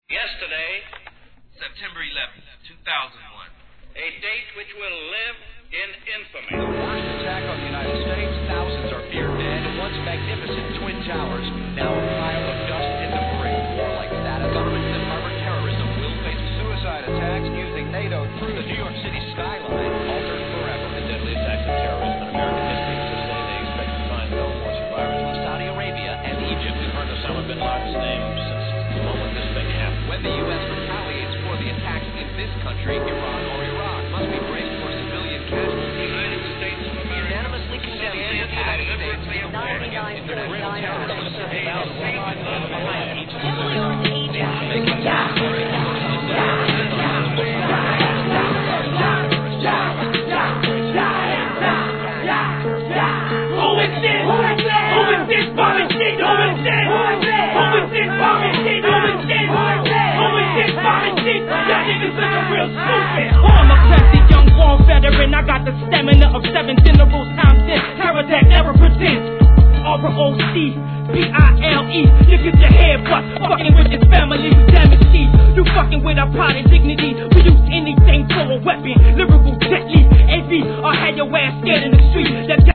G-RAP/WEST COAST/SOUTH
2001年、不穏なLOOPにダミ声Rapが強烈なダウナーBOUNCE!!